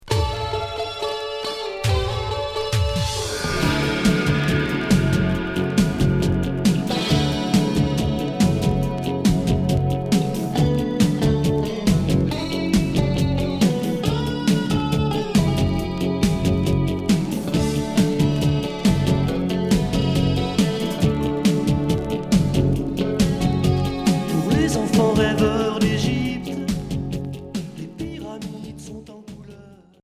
New wave Deuxième 45t retour à l'accueil